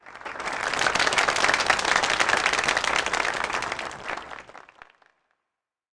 Applause Sound Effect
Download a high-quality applause sound effect.
applause-7.mp3